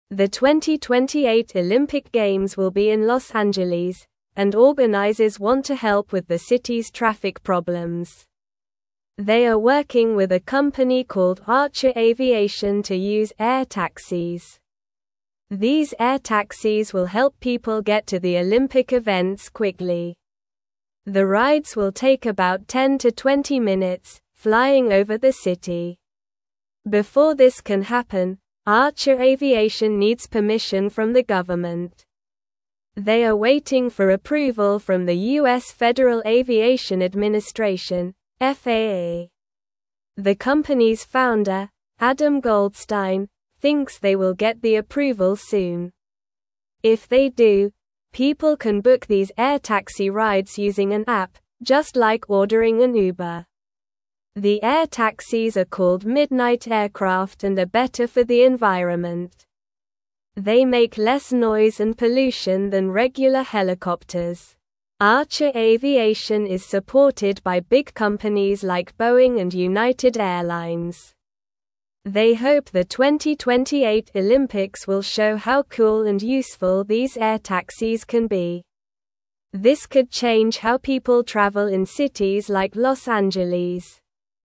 Slow
English-Newsroom-Lower-Intermediate-SLOW-Reading-Flying-Taxis-Could-Help-at-the-2028-Olympics.mp3